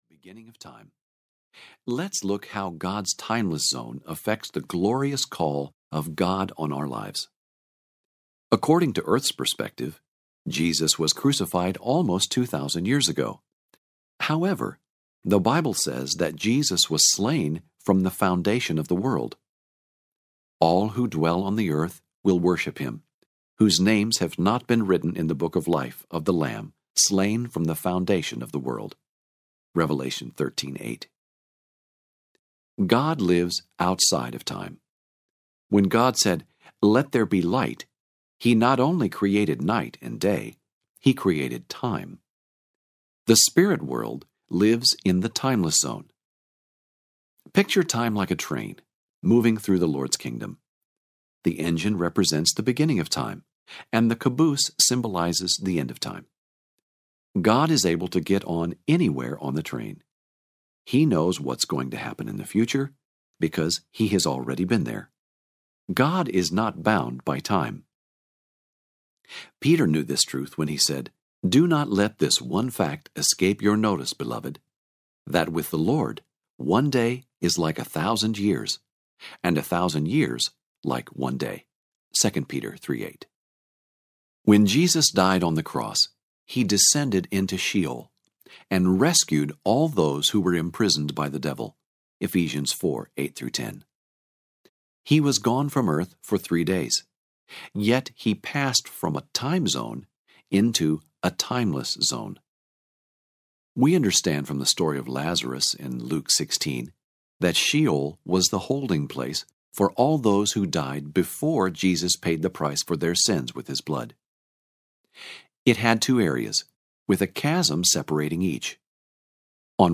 The Supernatural Ways of Royalty Audiobook